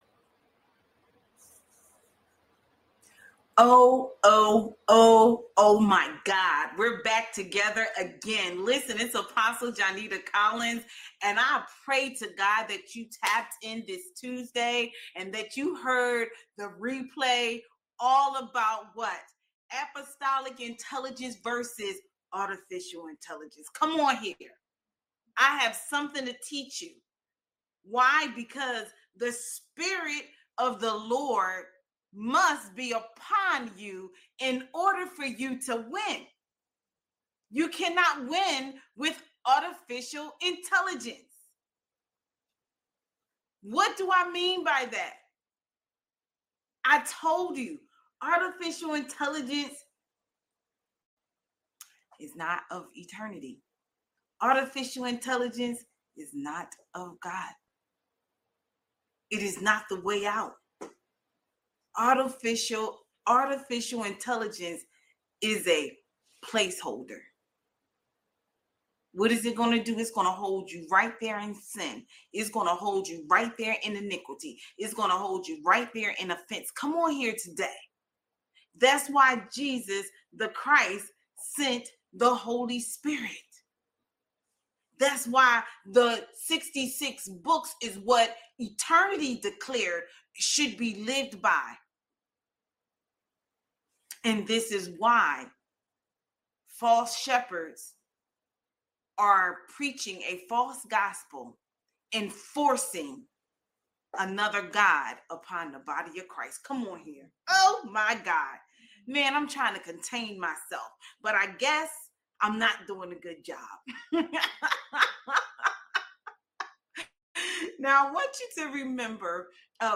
In this powerful message